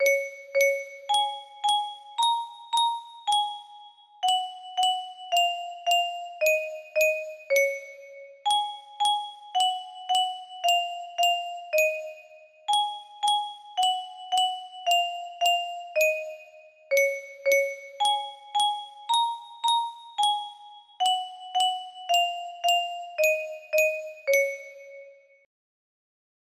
¿Estrellita donde estas? music box melody
Grand Illusions 30 music boxes More